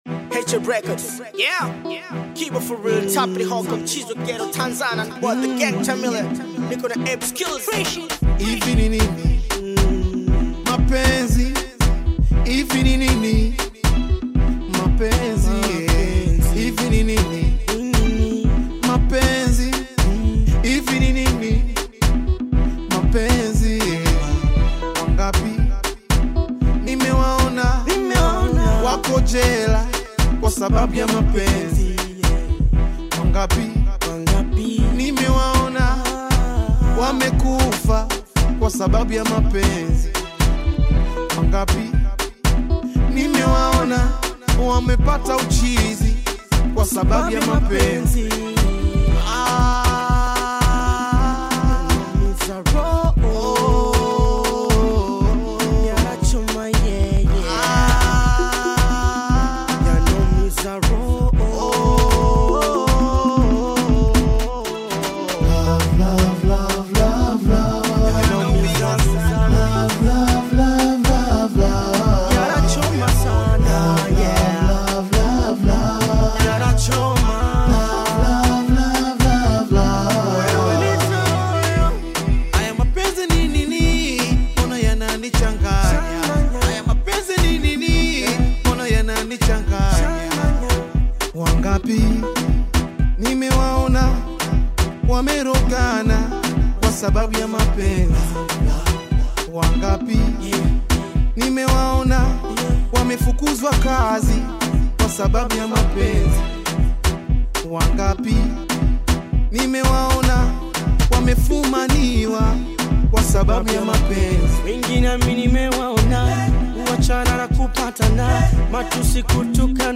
soulful Afro-Pop/Bongo Flava collaboration
blending emotive vocals and rich Swahili melodies